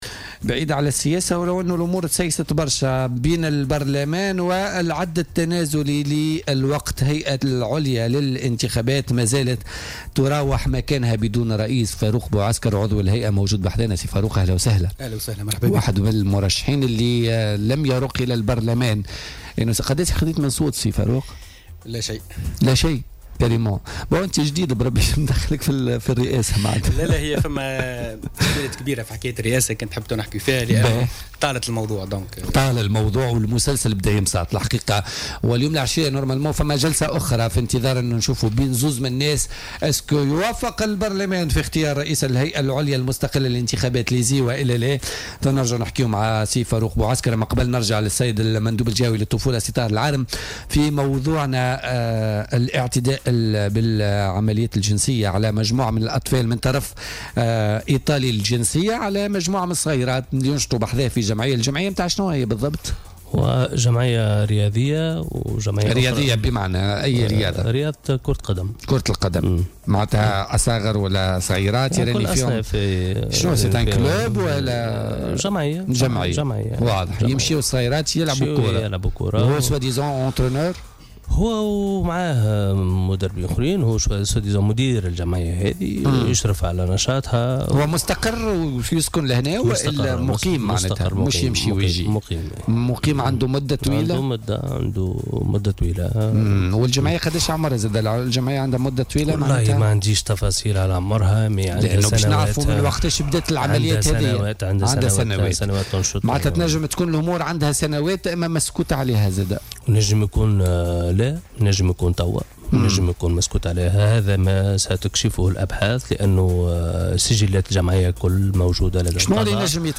Le délégué régional à la Protection de l'enfance de Sousse, Tahar Elarem, était l'invité ce lundi de l'émission Politica sur Jawhara FM pour éclairer l'opinion publique sur l'affaire de l'Italien soupçonné de viols sur des enfants à Hergla (gouvernorat de Sousse).